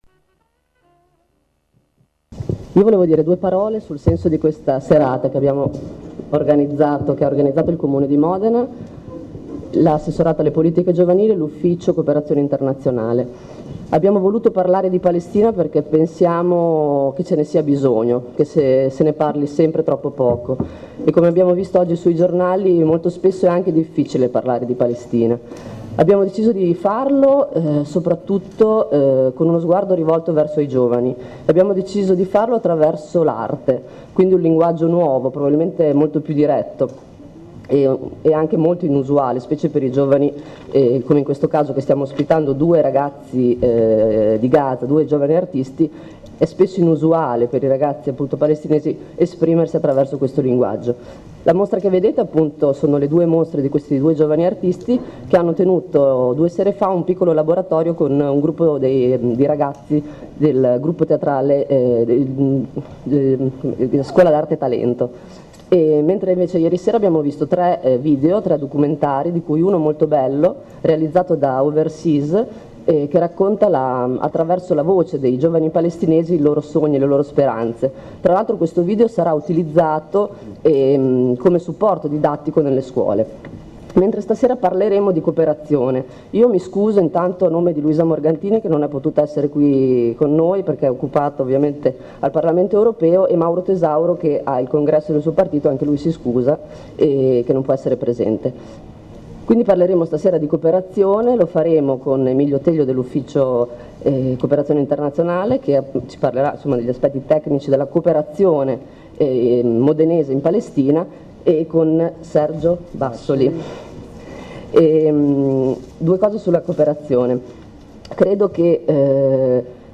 IL RUOLO E LE STRATEGIE DELLA COOPERAZIONE INTERNAZIONALE IL CASO DELLA PALESTINA Intervengono: ELISA ROMAGNOLI Assessore alle politiche giovanili del comune di Modena